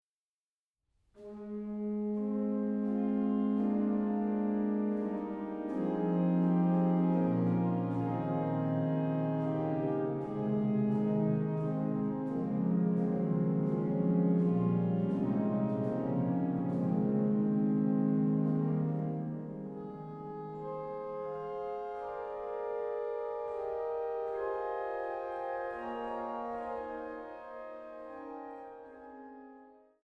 CD 2: Schwerin, Dom St. Marien und St. Johannis